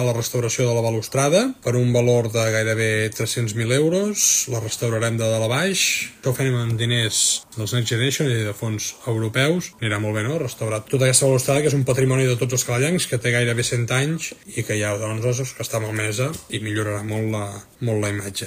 La inversió prevista puja a gairebé 250.000 euros i compta amb el finançament dels fons europeus NEXT GENERATION. Ho comentava l’alcalde Marc Buch en el live d’ahir a la plataforma Instagram.